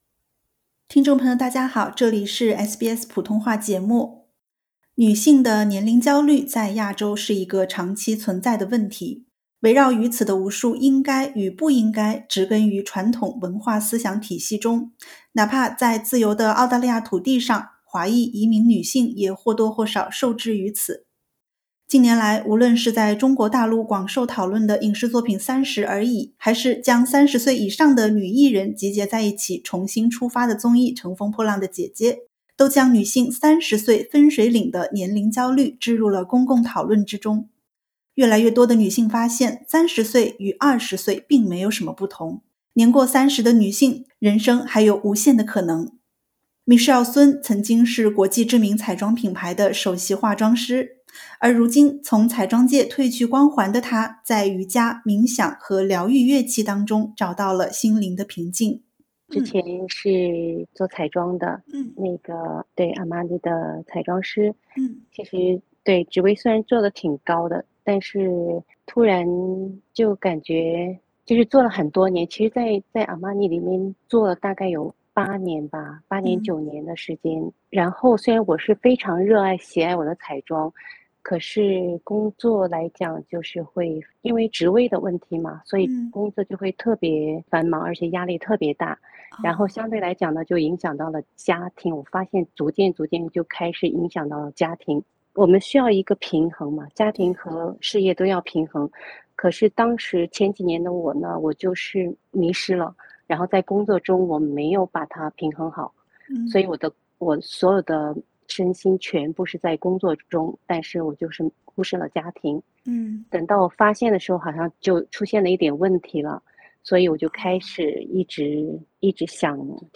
（点击图片收听完整对话）